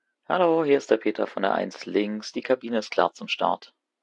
CallCabinSecureTakeoff.ogg